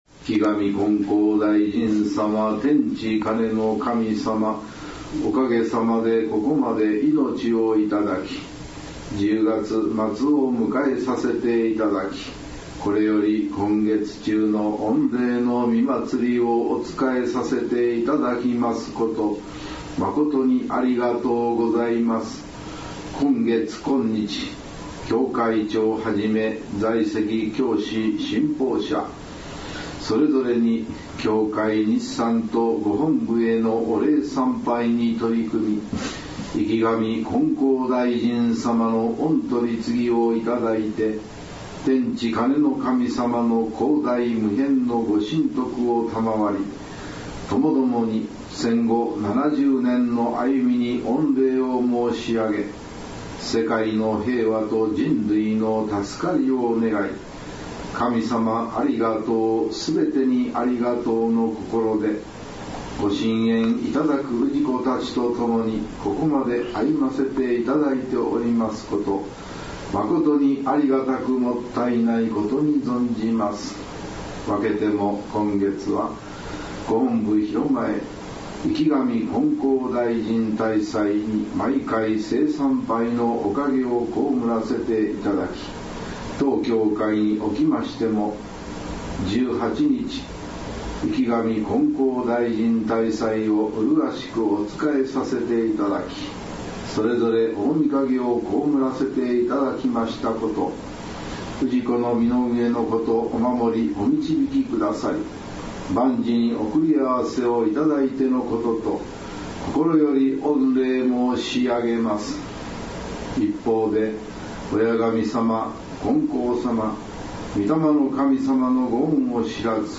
祭典では、１０月中のことを振り返りながら「祭詞」が奏上されました。
月末感謝祭祭詞（10月末）H27.10.30